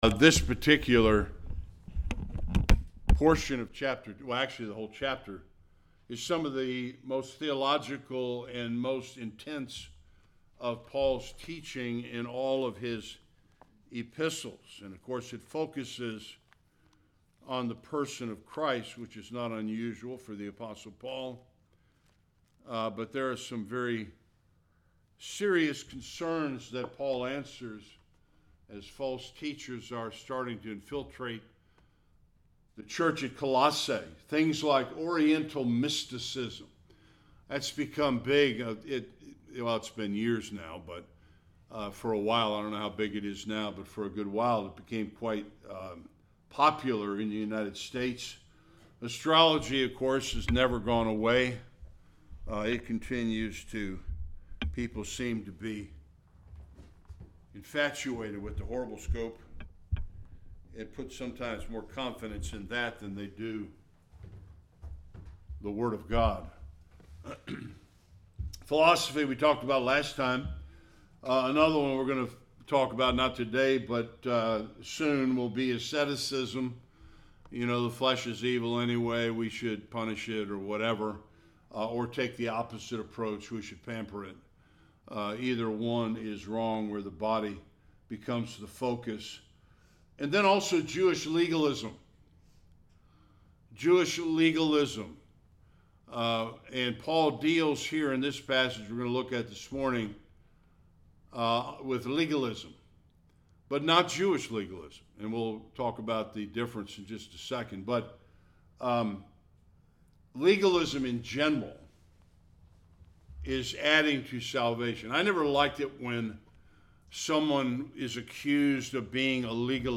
11 Service Type: Sunday Worship Paul addresses the false teachers faulty teaching on circumcision.